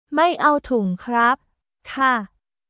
マイ アオ トゥング クラップ/カ